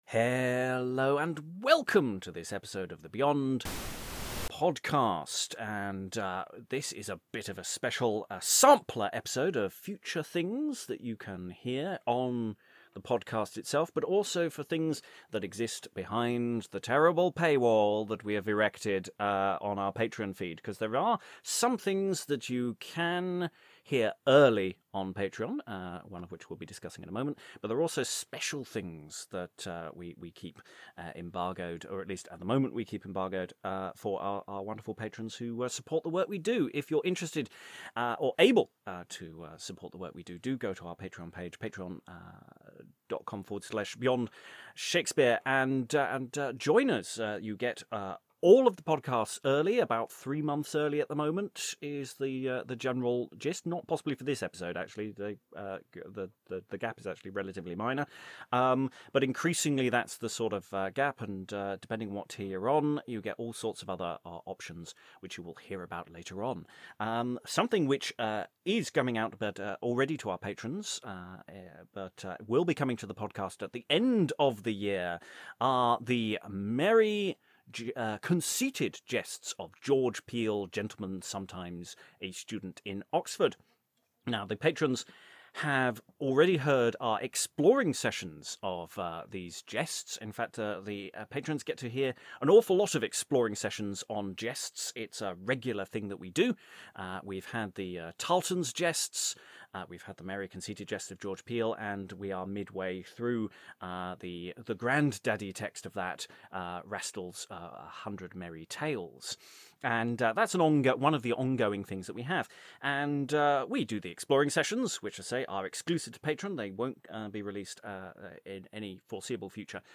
Discussion and preview for The Merry Conceited Jests of George Peele.